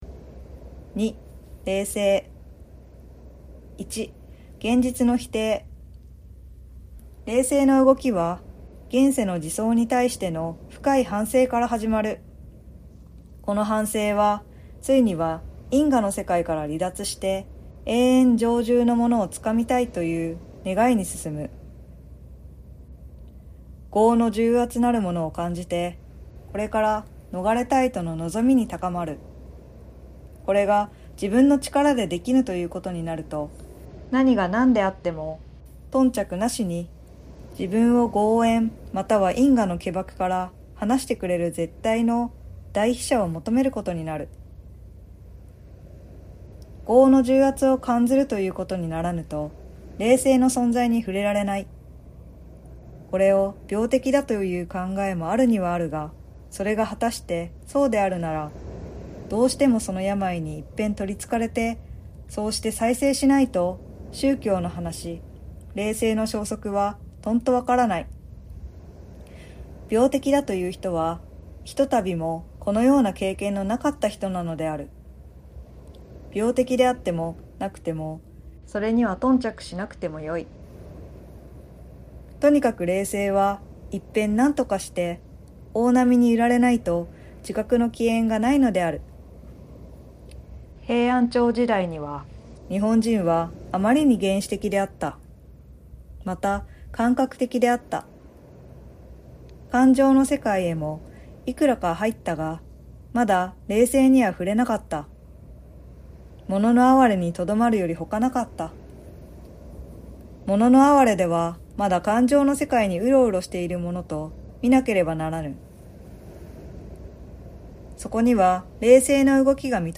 通勤中や家事の合間、眠る前のひとときなど、どうぞリラックスして、この静かで深い時間をお楽しみください。